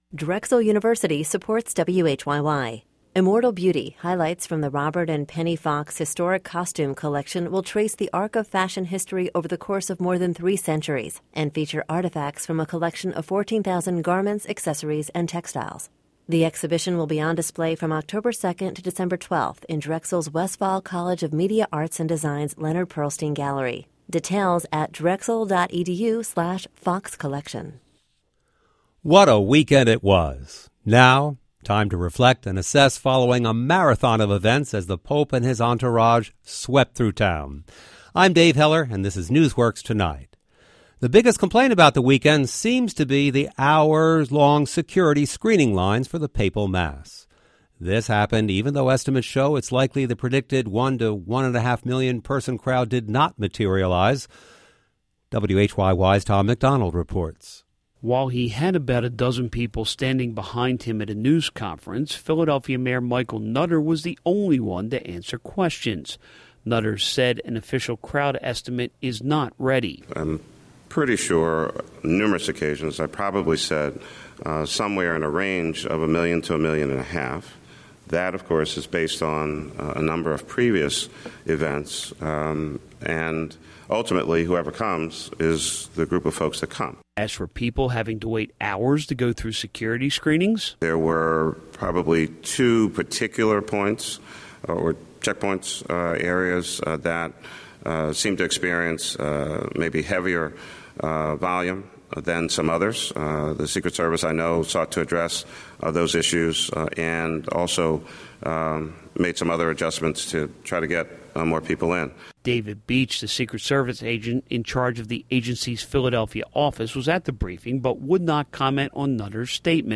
NewsWorks Tonight was a daily radio show and podcast that ran from 2011-2018.